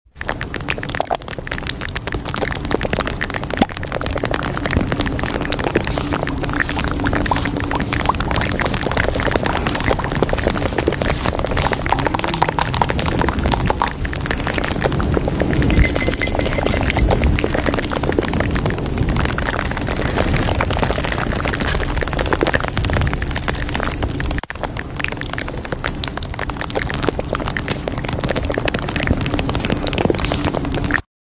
‹𝟹 ✿𝆬 free asmr sound sound effects free download